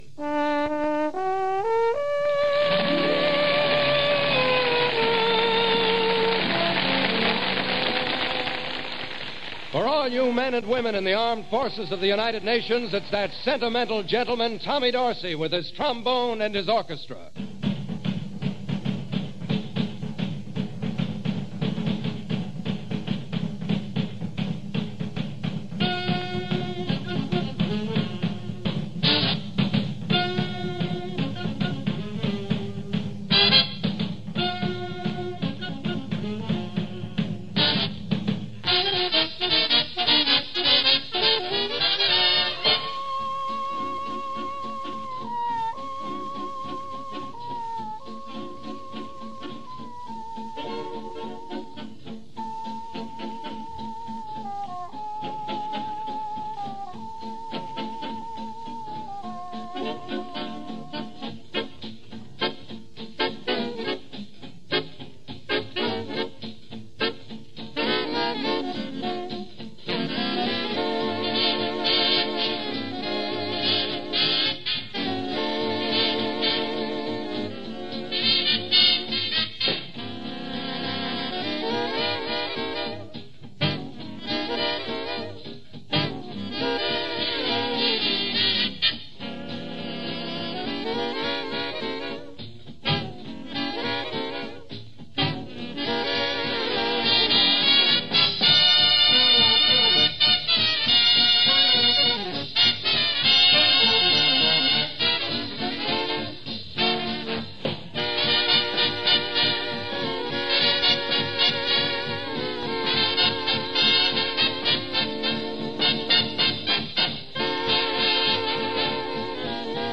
Swing Era and World War 2.
Swing Era
Big Band